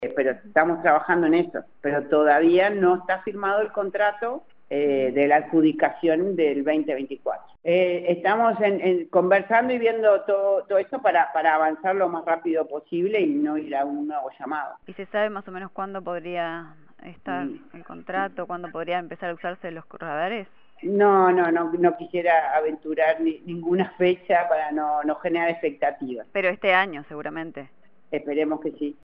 La presidenta del Instituto Uruguayo de Meteorología, Madeleine Renom, dijo a Informativo Uruguay que aún no se ha firmado el contrato con la empresa porque el directorio completo del organismo asumió en octubre pasado, pero indicó que esperan que se firme este año.